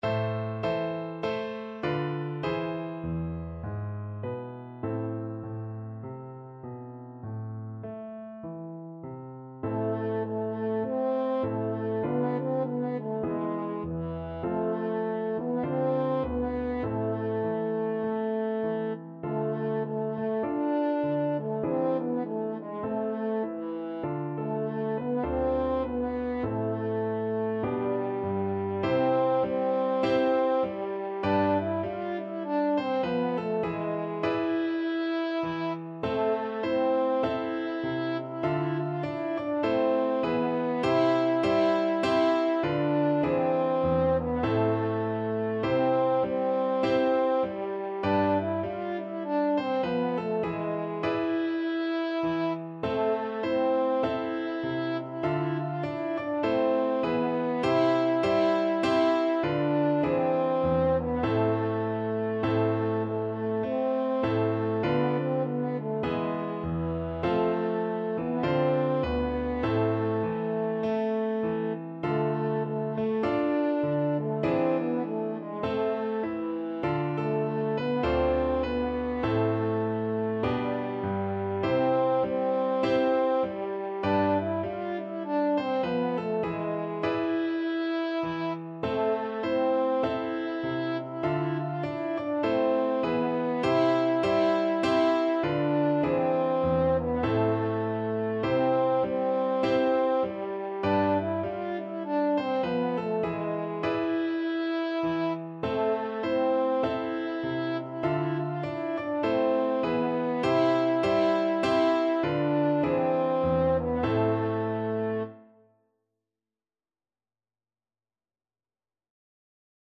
French Horn version
Traditional Music of unknown author.